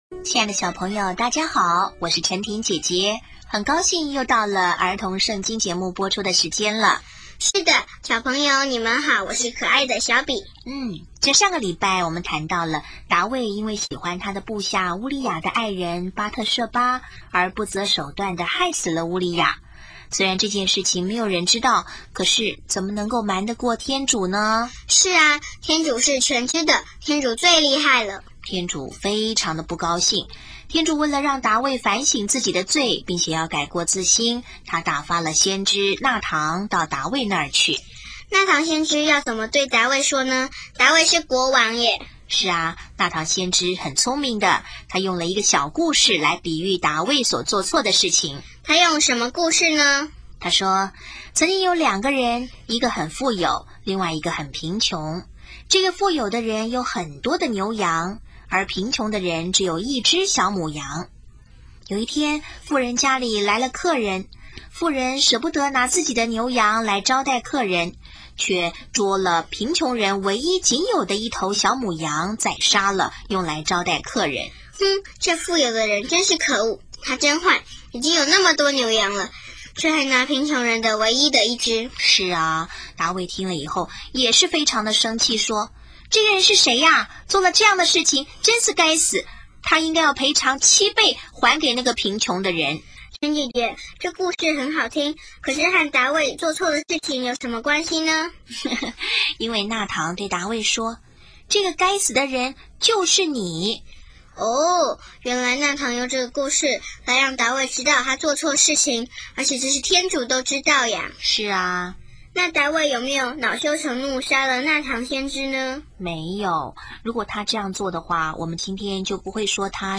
【儿童圣经故事】27|达味(四)悔罪圣咏